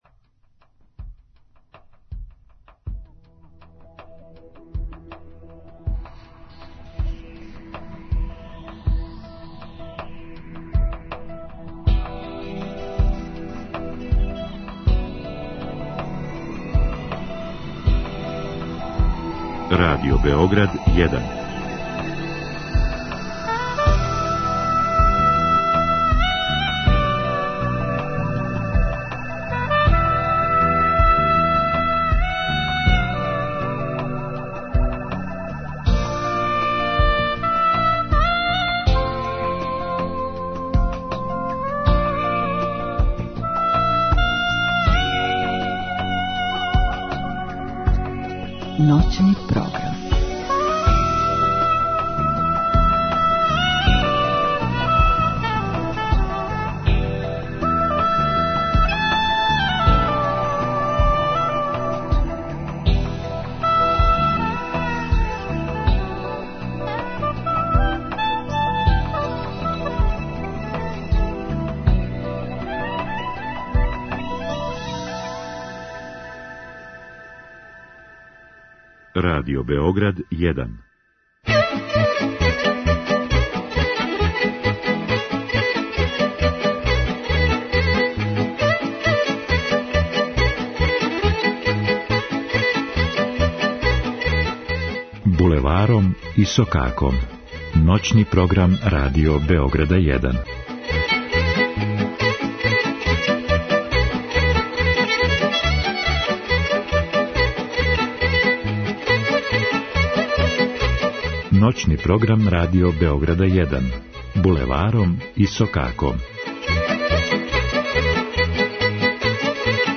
Изворна, староградска и музика у духу традиције.